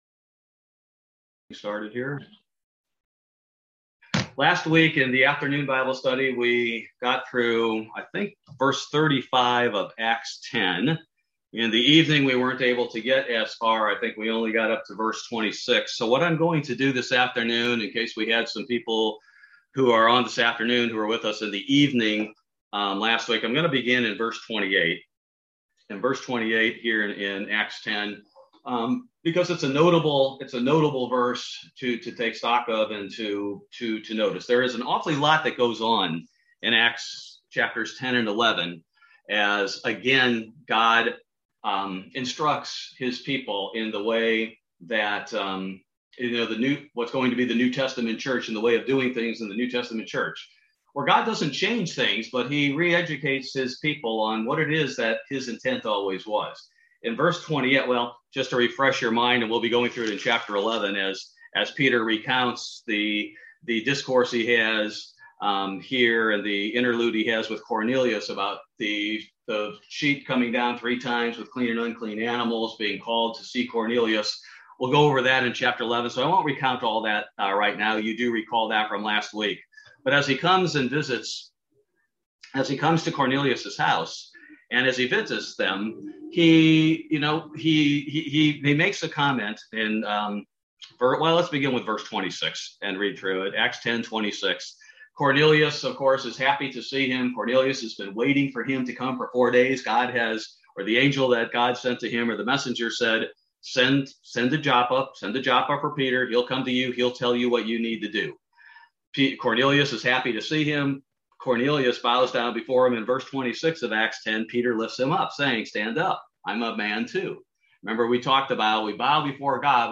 Bible Study: July 28, 2021